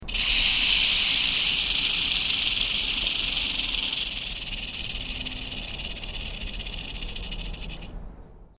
rattlesnake-sound.mp3
rattlesnake.mp3